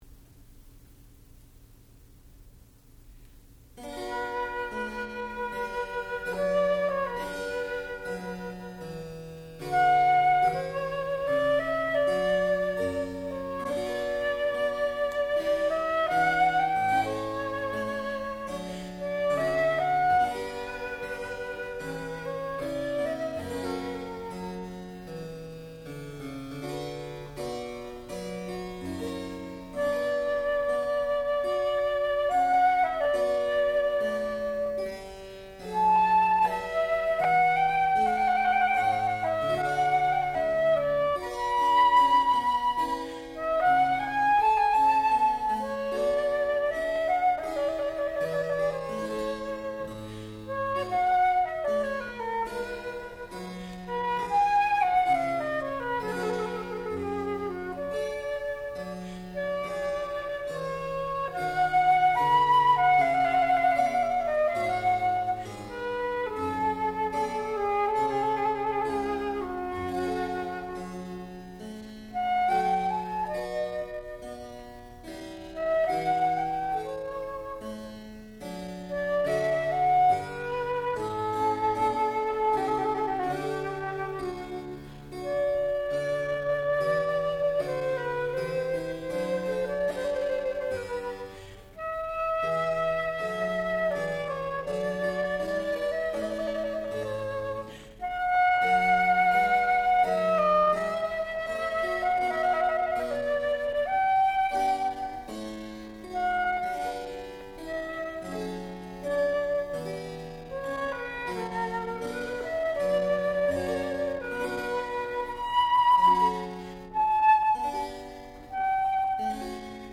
sound recording-musical
classical music
harpsichord
flute
Junior Recital